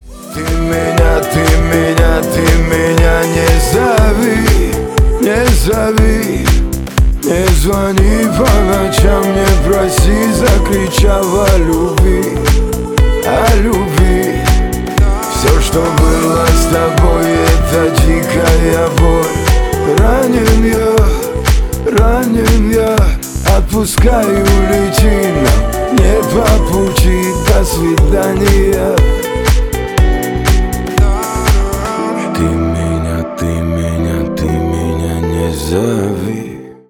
Stereo
Поп